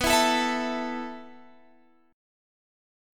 A5/B chord